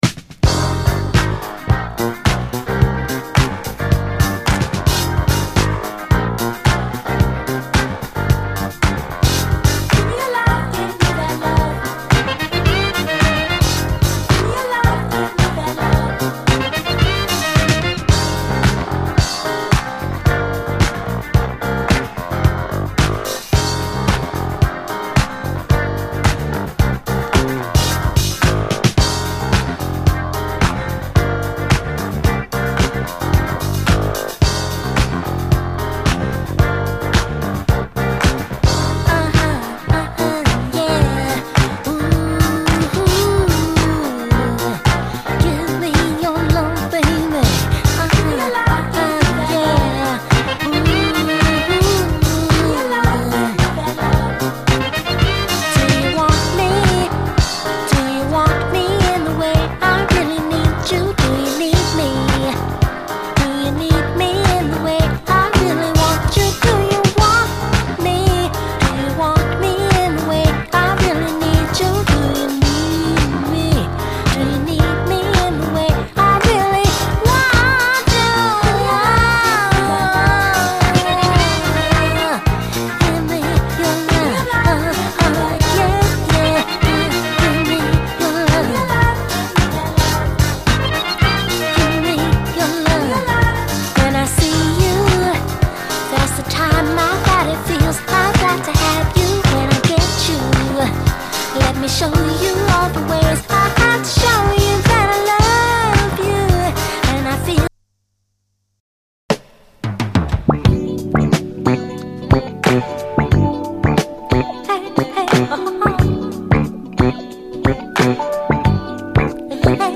SOUL, 70's～ SOUL, DISCO
しなやかなグルーヴのガラージ〜ディスコ・クラシック